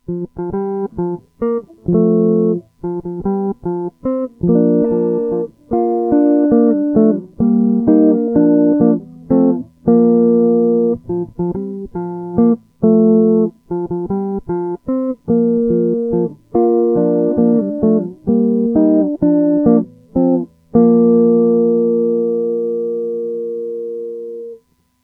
Happy Birthday on guitar
In the example I’m playing the melody in the chords but if you’ve got folks singing along with you, just play the chords!
If you start in G like the example the chords move like this G / D / G  / C / G /  D / G .